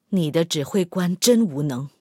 SU-122A夜战攻击语音.OGG